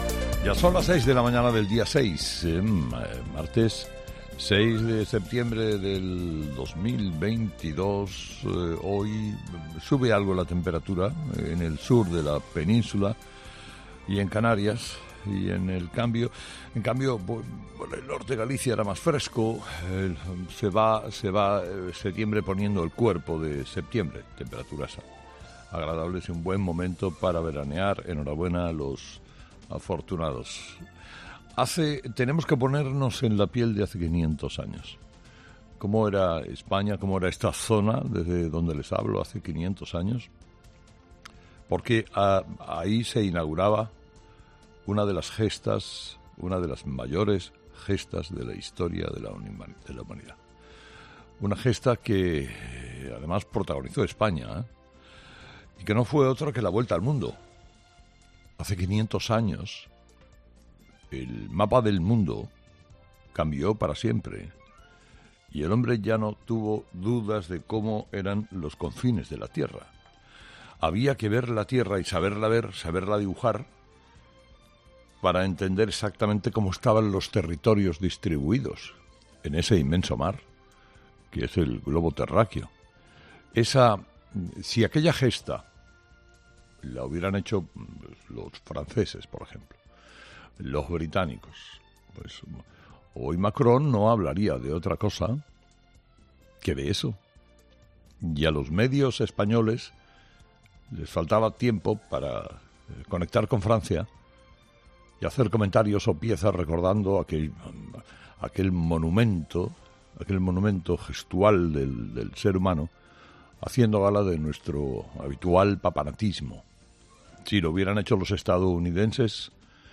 Carlos Herrera analiza los principales titulares que marcarán la actualidad este jueves 8 de septiembre en nuestro país